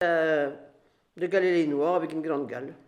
Localisation Saint-Hilaire-des-Loges
Catégorie Locution